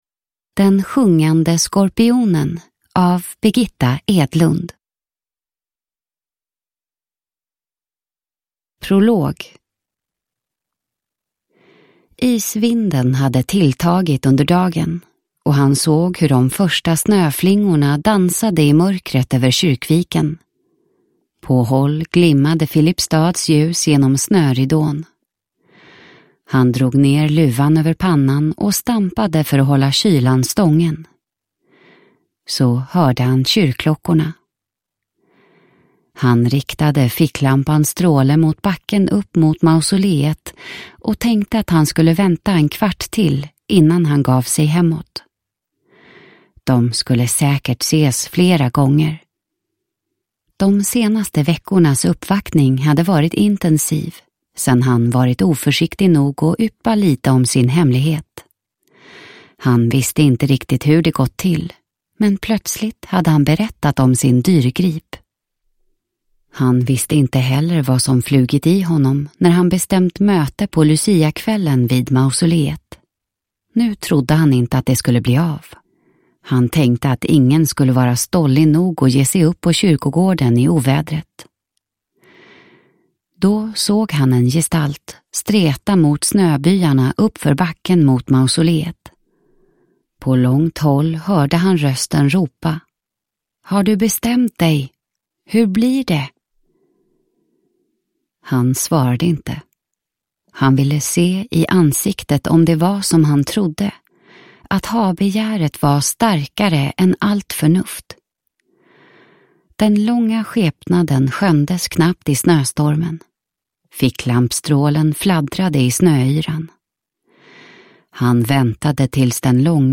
Den sjungande skorpionen – Ljudbok